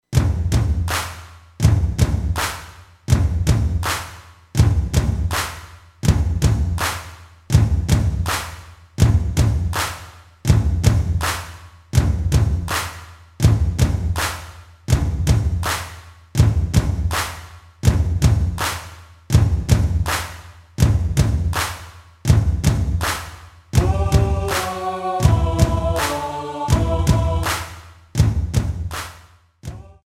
klick & play MP3/Audio demo